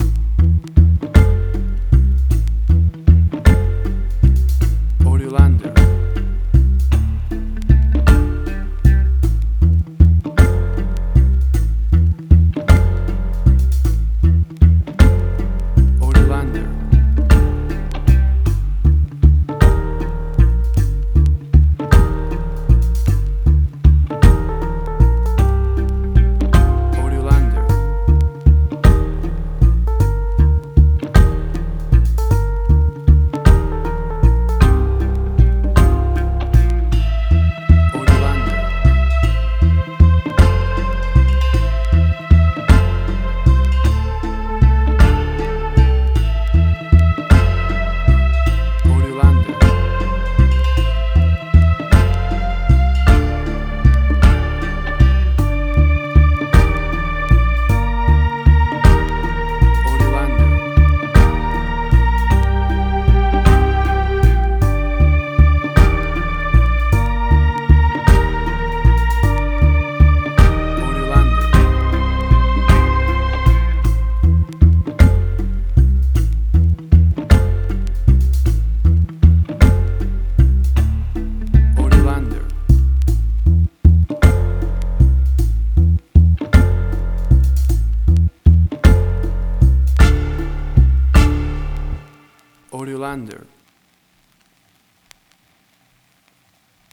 Reggae caribbean Dub Roots
Tempo (BPM): 52